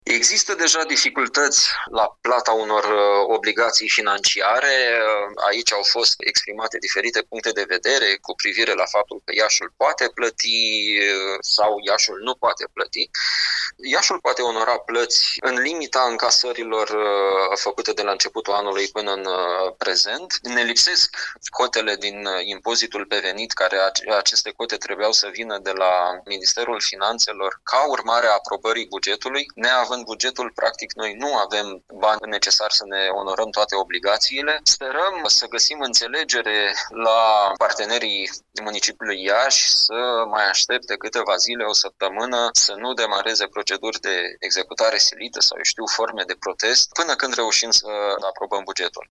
Liderul grupului consilierilor liberali din CL Iaşi, Eduard Boz a atras astăzi atenţia în şedinţa consiliului local că întârzierile în votarea bugetului pot determina blocarea conturilor primăriei.